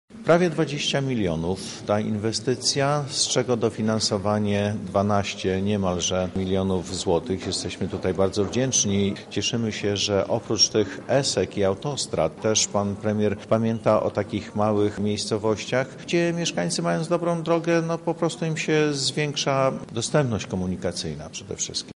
• mówi Dariusz Szustek, starosta powiatu łukowskiego.